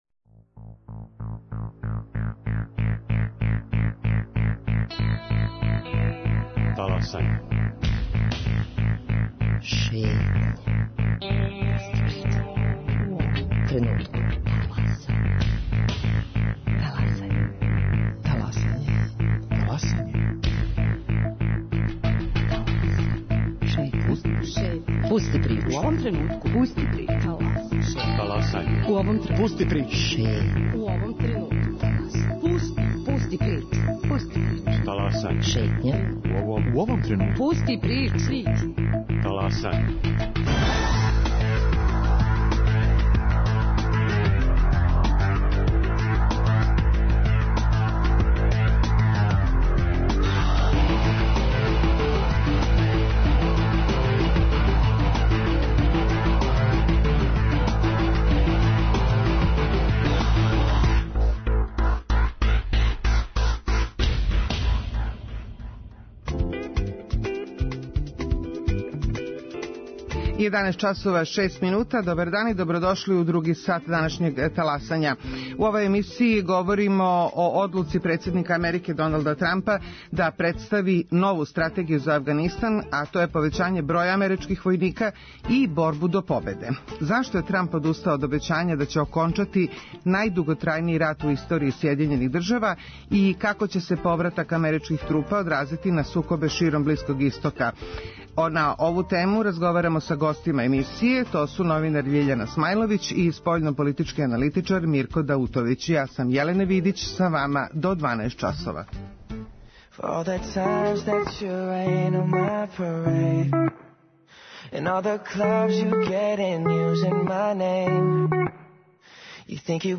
новинар Љиљана Смајловић